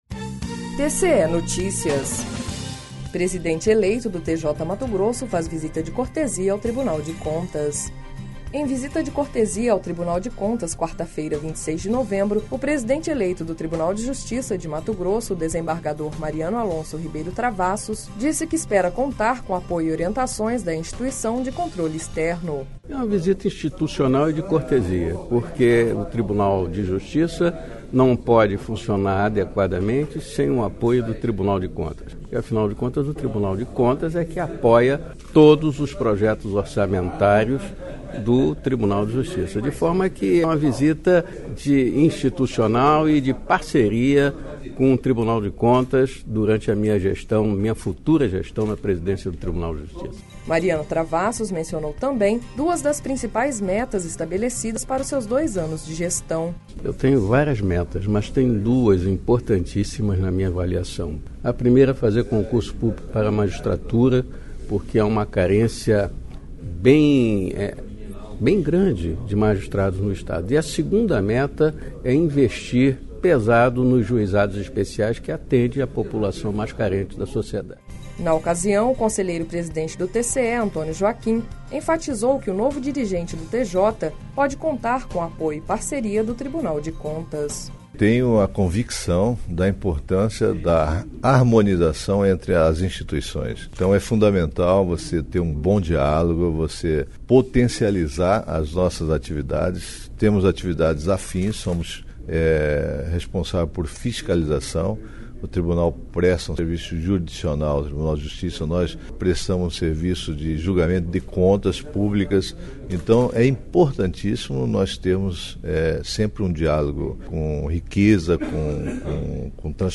Sonora: Mariano Alonso Ribeiro Travassos – presidente eleito TJ/MT
Sonora: Antonio Joaquim – conselheiro presidente do TCE - MT